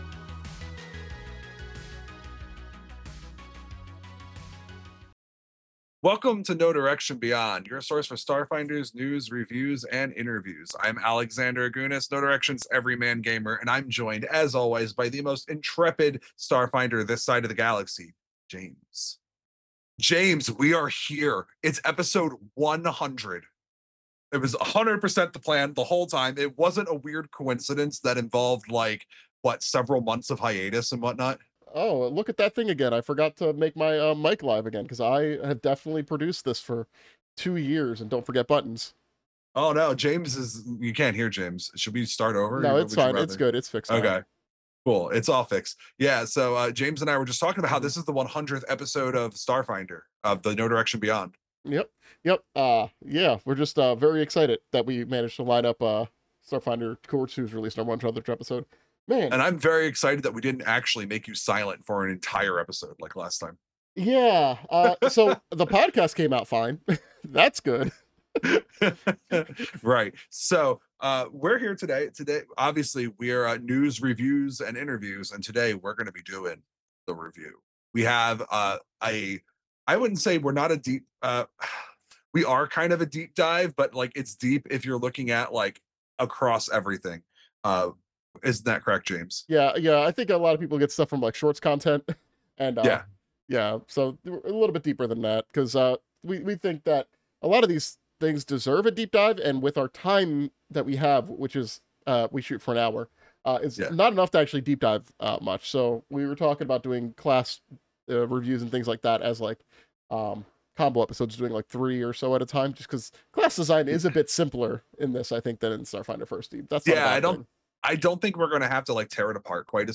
Recorded live on Twitch.